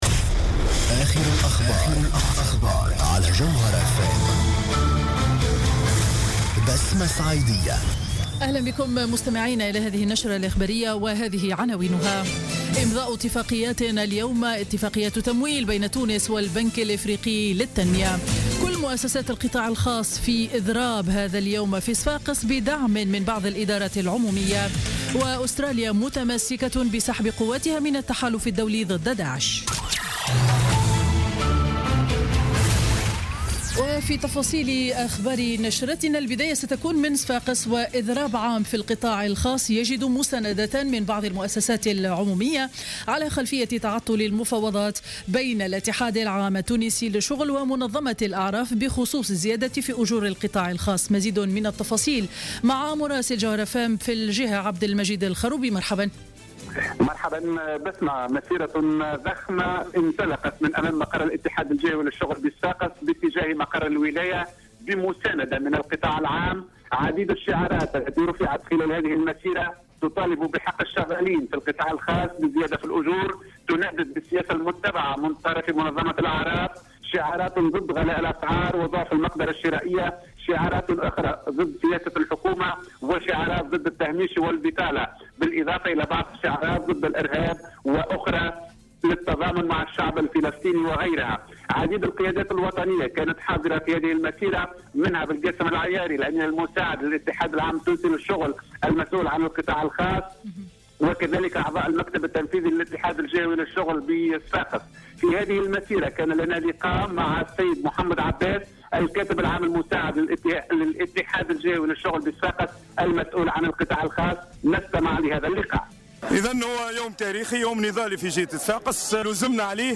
نشرة أخبار منتصف النهار ليوم الخميس 19 نوفمبر 2015